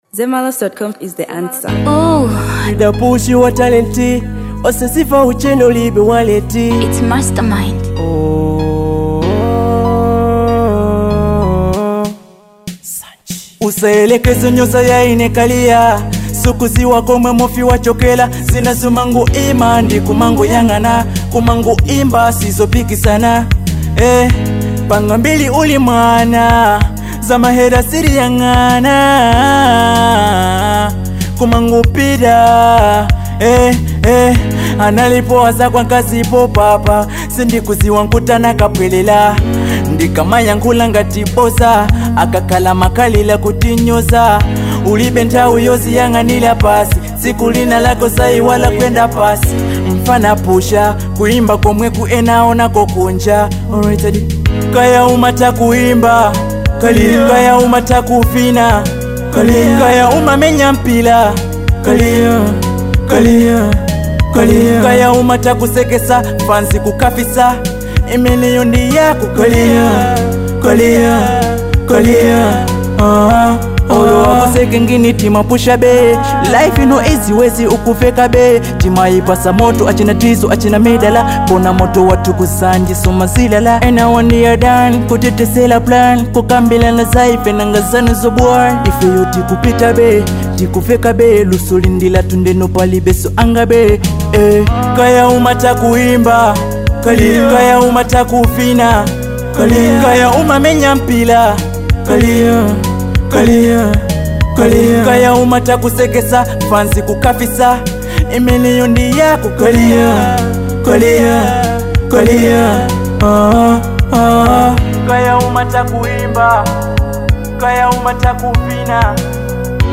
Genre: Dancehall.